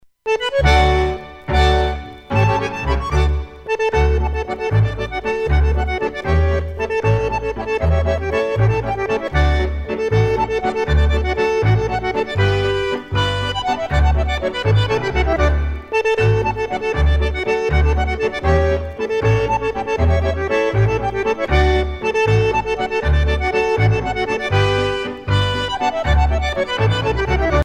danse : ländler
Pièce musicale éditée